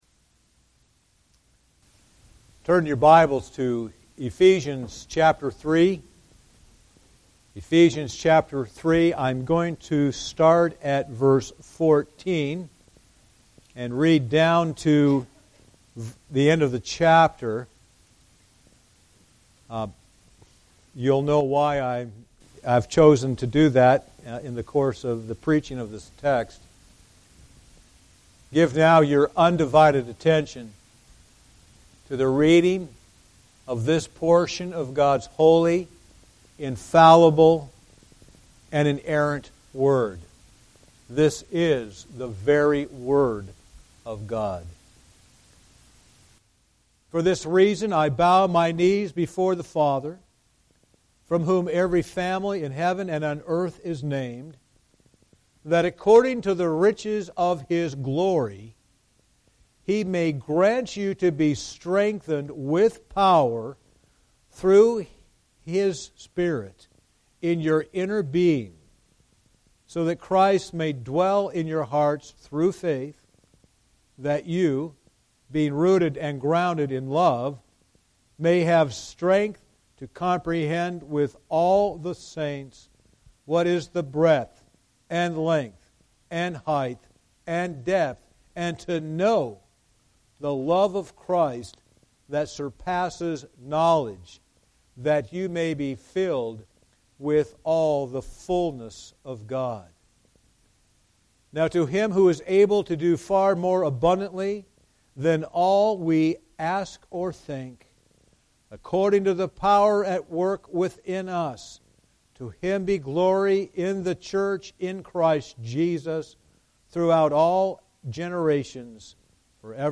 Guest Preachers Passage: Ephesians 3:14-21 Service Type: Sunday Evening Service « A Prayer for Enlightenment The Righteous God